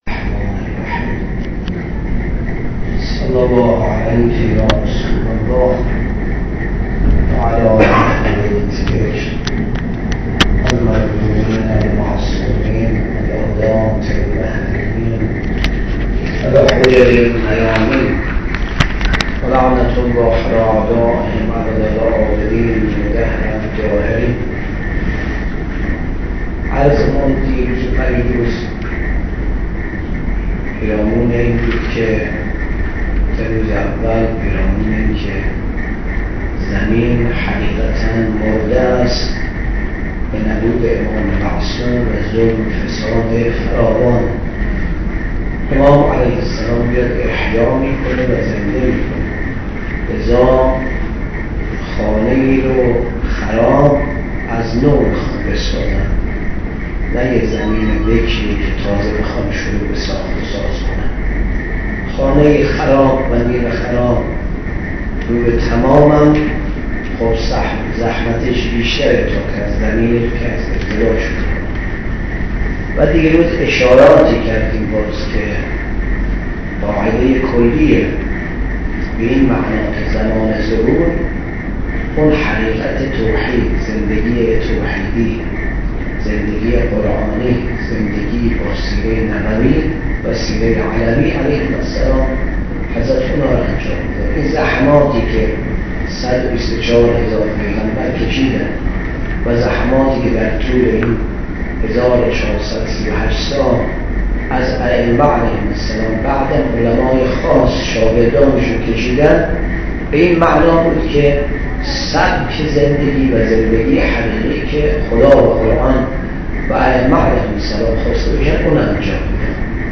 20 اردیبهشت 96 - جلسه اول امام مهدی شناسی - حرم حضرت معصومه